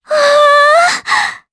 Artemia-Vox_Happy4_jp.wav